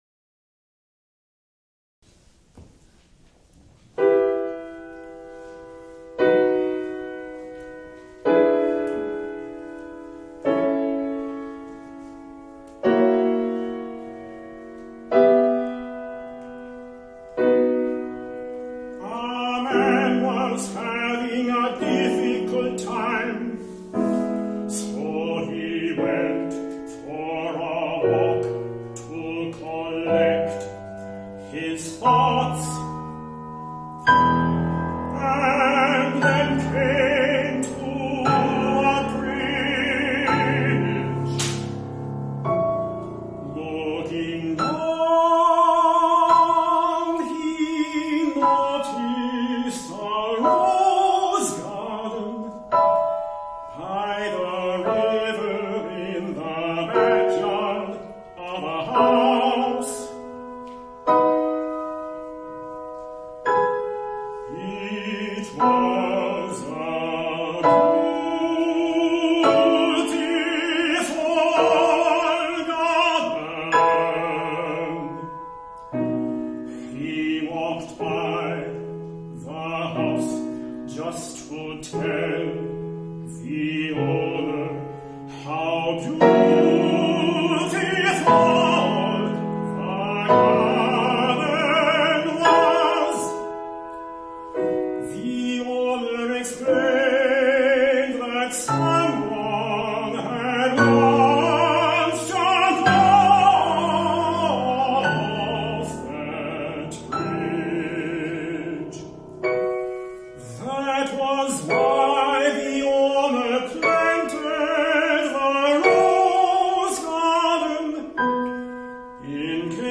As in some other recent works of mine, it may actually sound “pretty”. The entire cycle is a set of seven variations.
Each of the seven songs is put in a key a fourth higher than the preceding one. Modes change from minor to major (and a bit beyond that) according to the implications of the text.